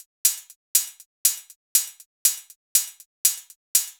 Session 14 - Hi-Hat 01.wav